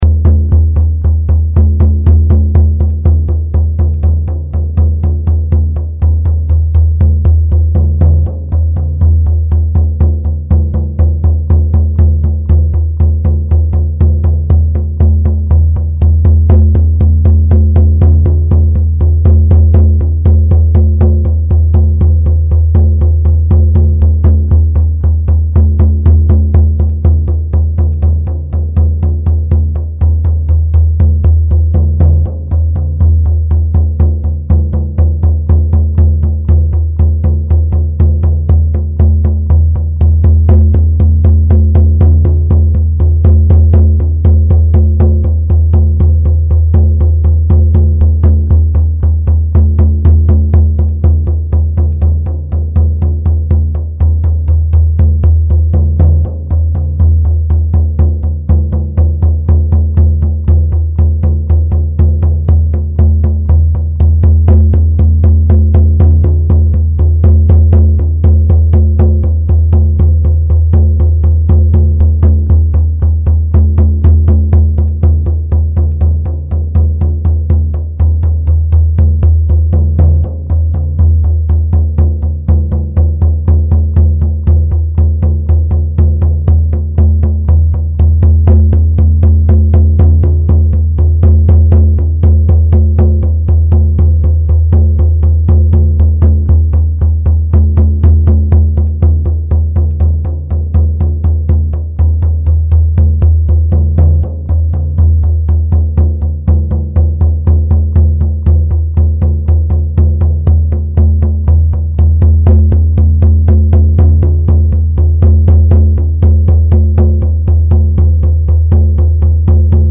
Drumming for a vision
Visioning-drumming.mp3